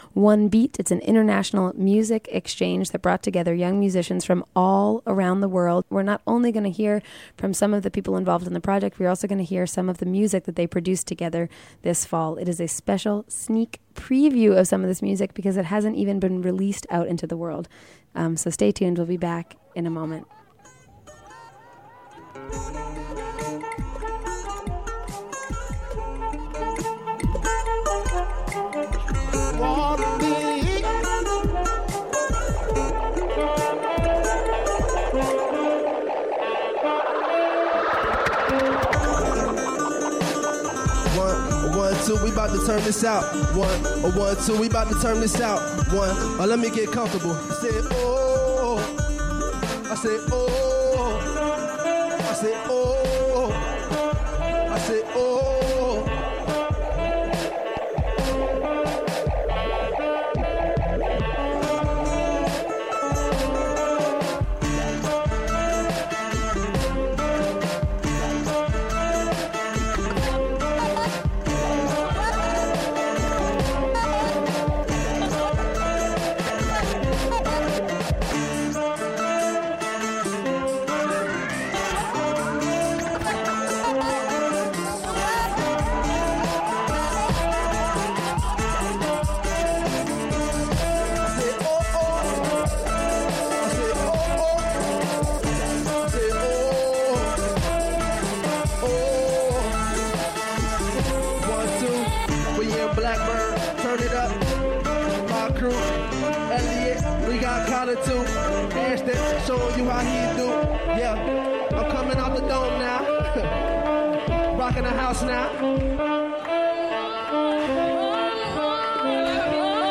and we hear a excerpts of some of the music produced.